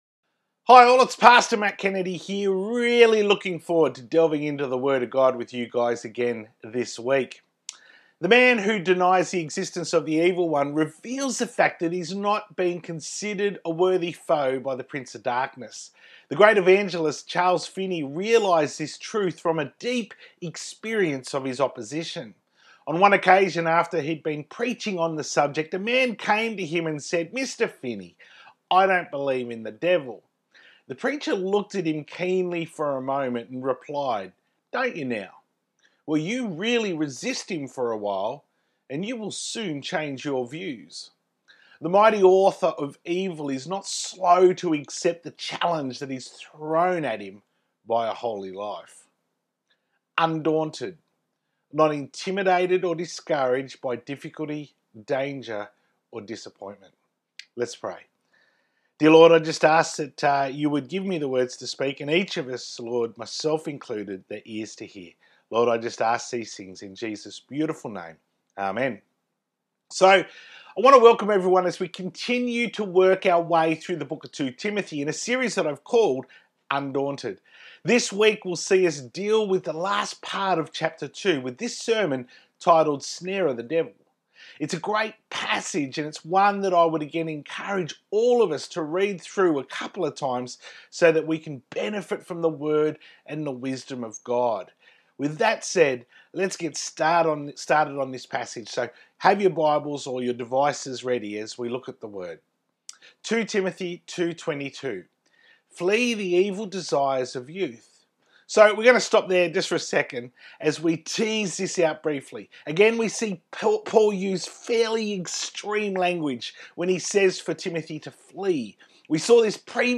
To view the Full Service from 13th December 2020 on YouTube, click here.